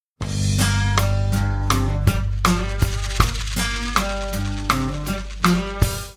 funk_bluesfollow.mp3